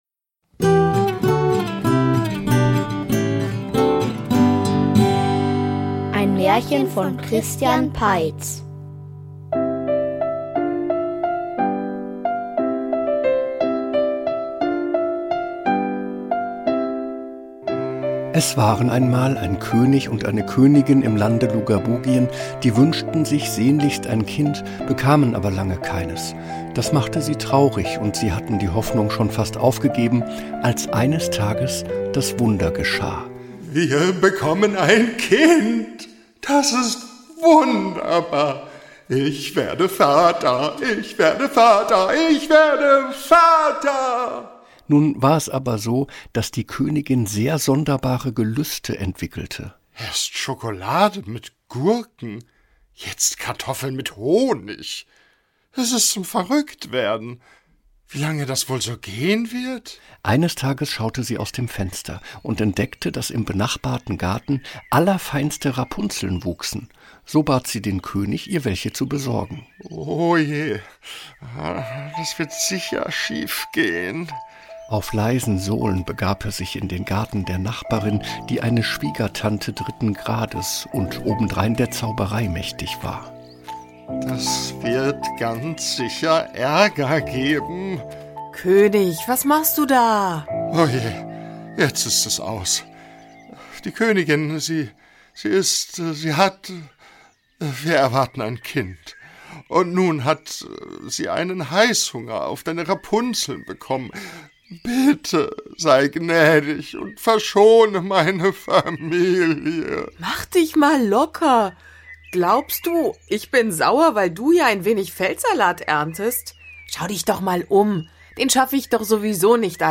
Die Prinzessin, der Turm und das sehr große Übel --- Märchenhörspiel #65 ~ Märchen-Hörspiele Podcast